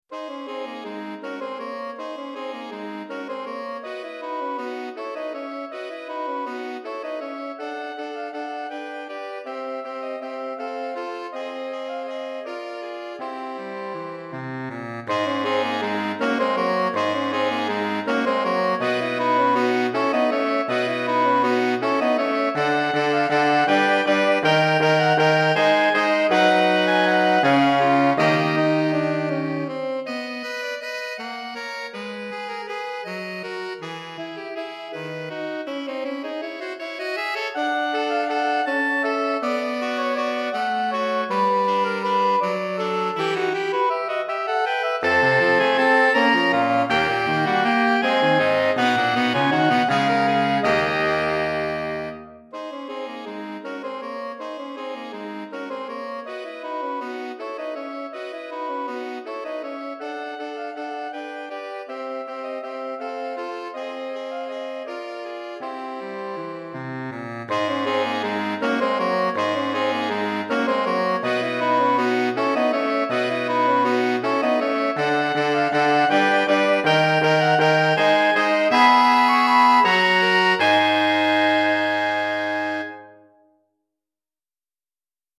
4 Saxophones